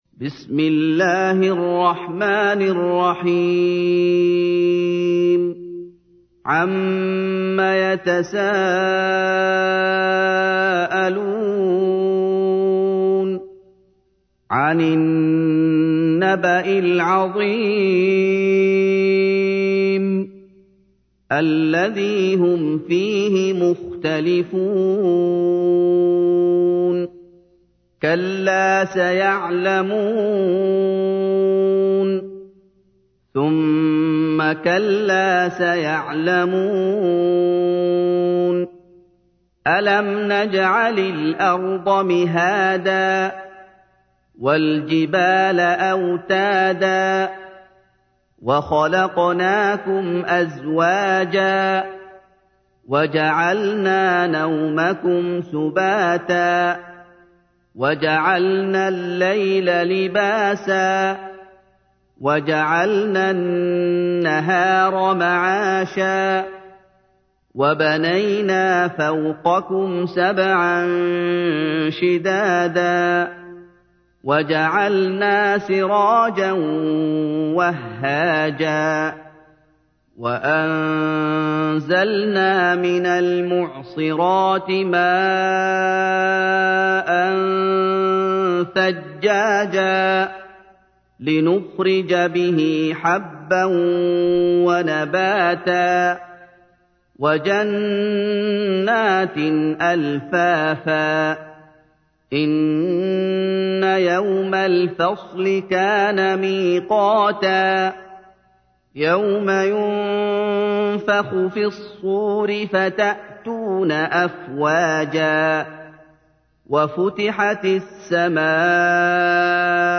تحميل : 78. سورة النبأ / القارئ محمد أيوب / القرآن الكريم / موقع يا حسين